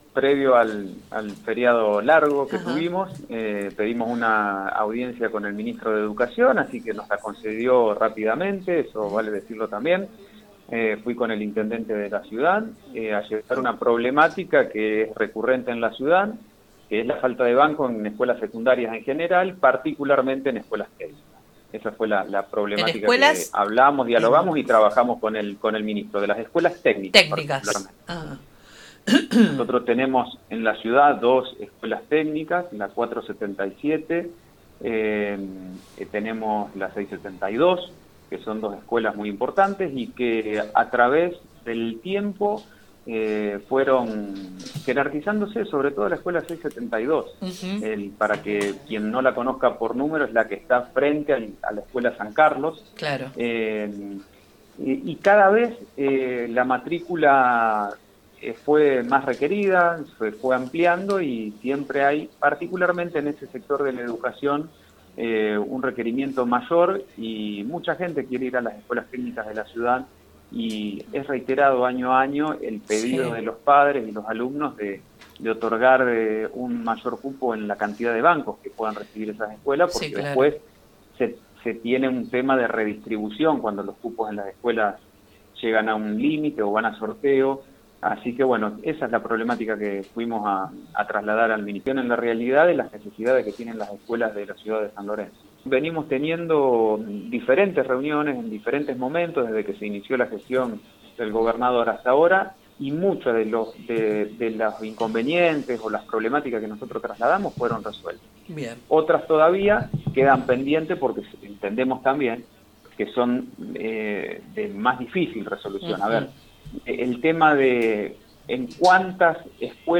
En contacto con el programa “Con Voz” de Radio 102.9, el concejal Ore indicó que uno de los ejes centrales de la reunión fue la falta de bancos en las escuelas técnicas, especialmente en la Escuela Técnica Nº 477 y la Escuela Técnica Nº 672, donde la demanda de estudiantes supera ampliamente la capacidad disponible.